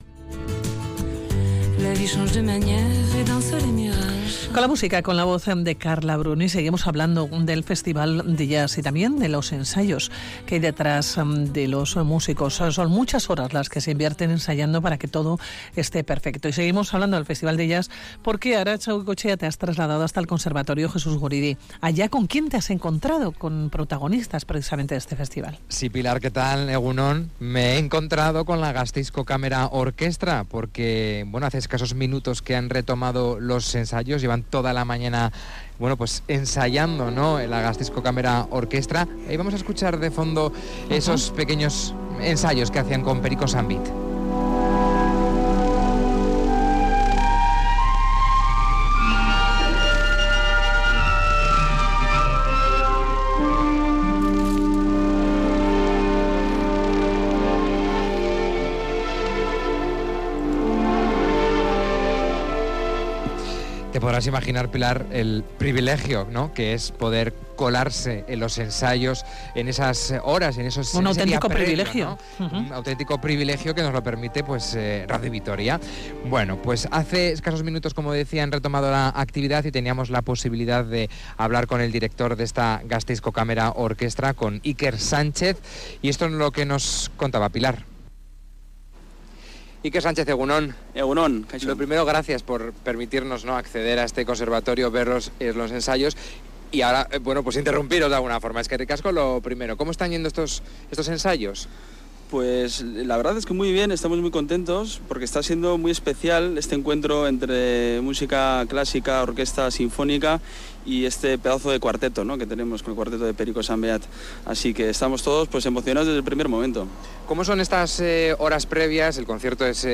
Nos colamos en los ensayos de la Gasteizko Kamera Orkestra
música sinfónica
Un repertorio amplio desde Ravel, a Satie o el misterio étnico y disonante de Bela Bártok.